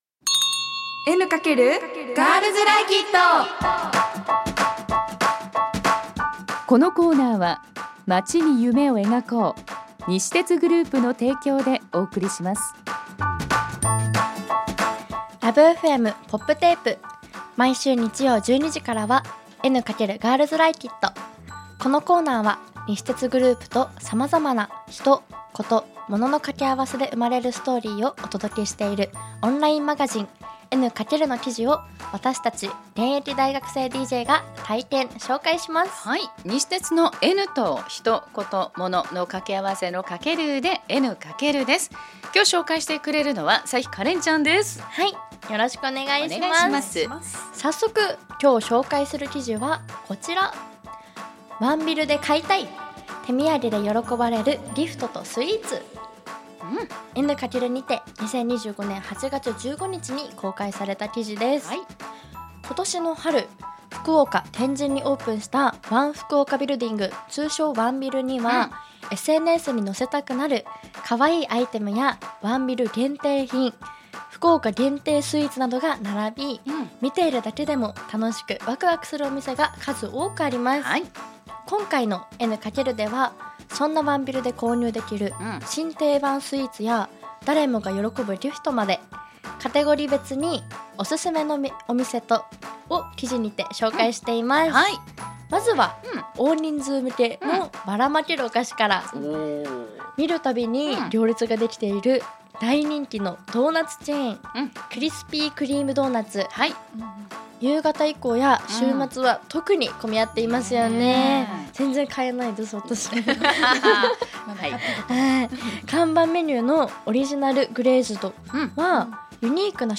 女子大生DJが「N× エヌカケル」から気になる話題をピックアップ！